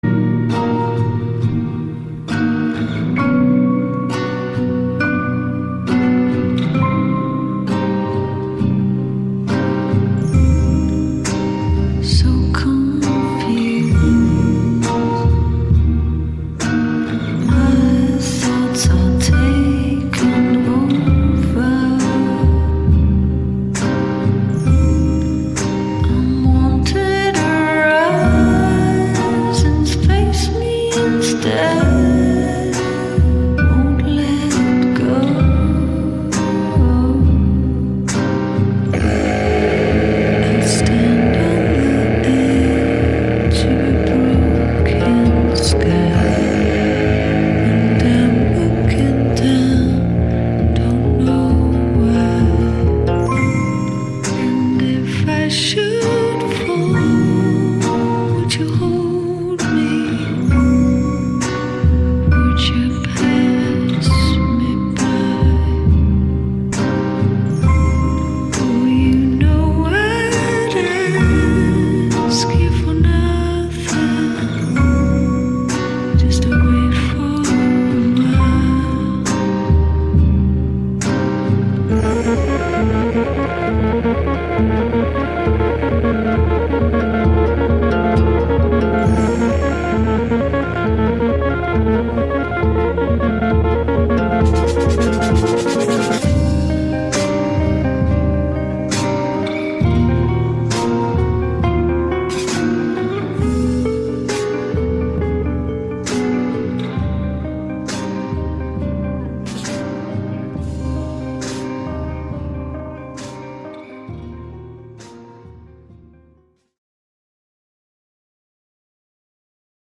Im Freiland ohne Plan senden wir diese Folge direkt in den digitalen und analogen Äther. Wir reden über unsere Lieblingsthemen: Wahlfuckups, Datenschutz und Unsinn.